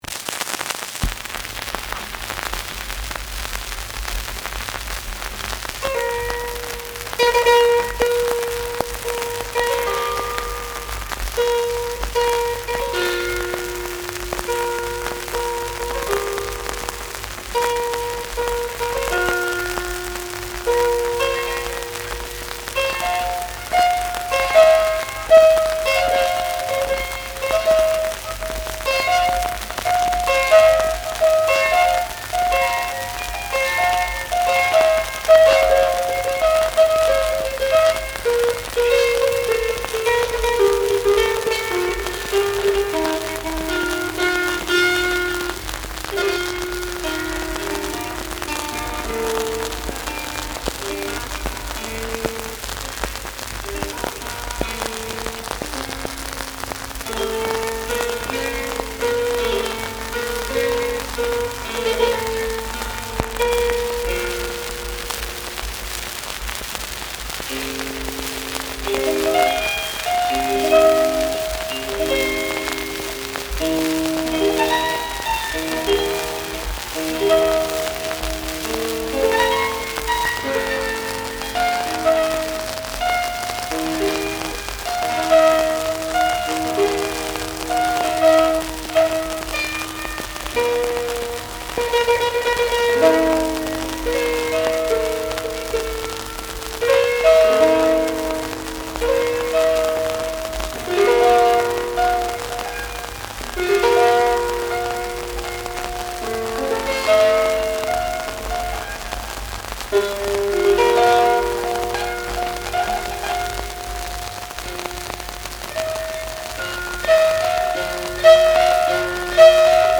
I have transferred them using a hifi turntable and lightweight pickup.
on the Bardic Harp